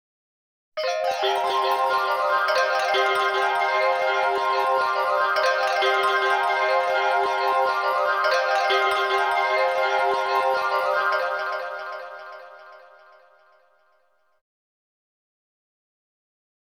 04_specialsfx_25_SQ.wav